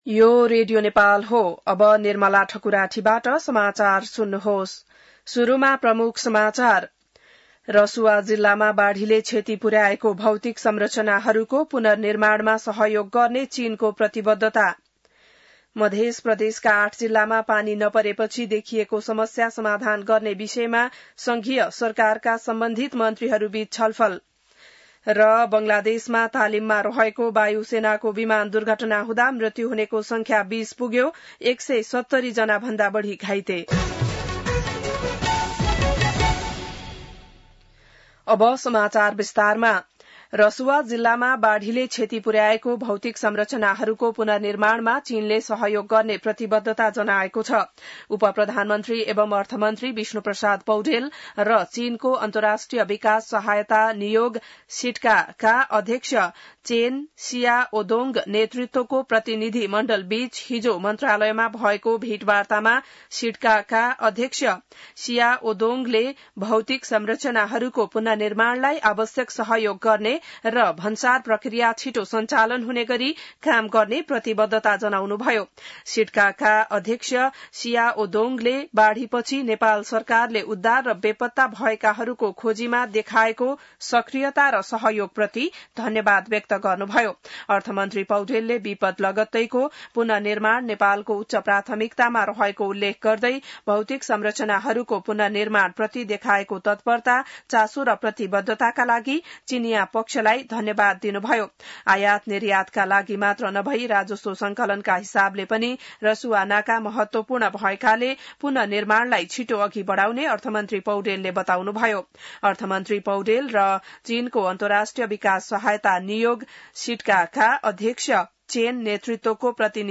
बिहान ९ बजेको नेपाली समाचार : ६ साउन , २०८२